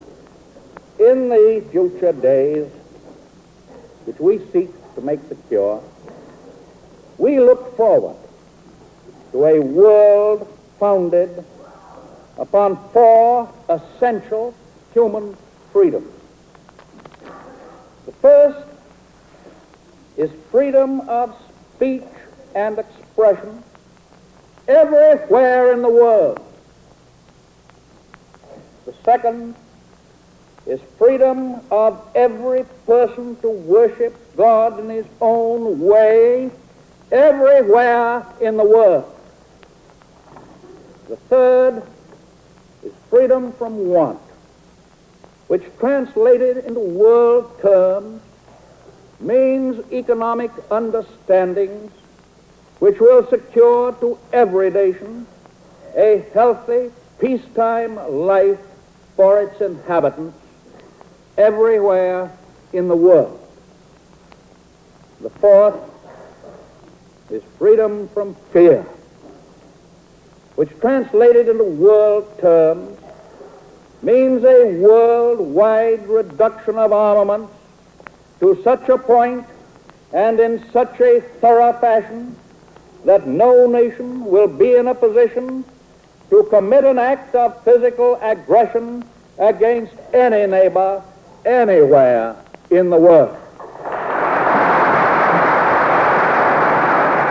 The above is from the State Of The Union Address delivered by Franklin Delano Roosevelt on January 6, 1941. It is called Four Freedoms; the full audio and transcript can be found here.